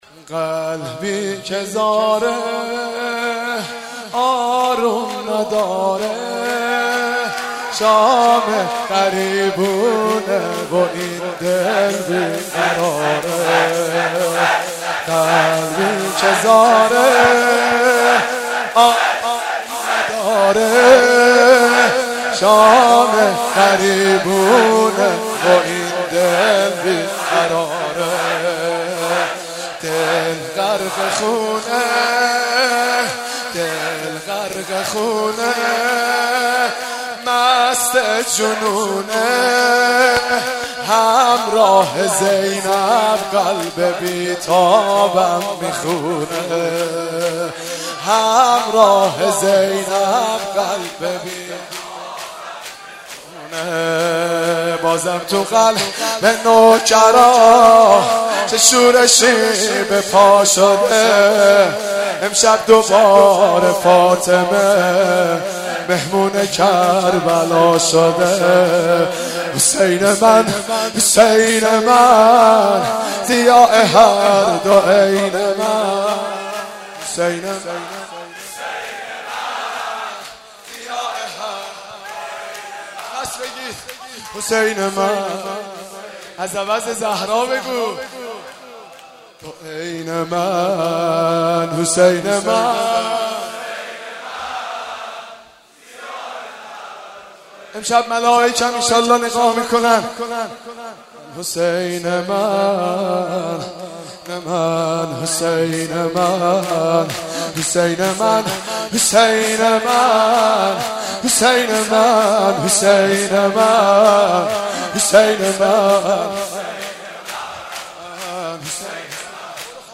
شب یازدهم محرم1394